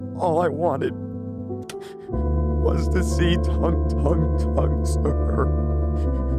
Crying